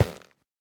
Minecraft Version Minecraft Version 1.21.5 Latest Release | Latest Snapshot 1.21.5 / assets / minecraft / sounds / block / fungus / break5.ogg Compare With Compare With Latest Release | Latest Snapshot
break5.ogg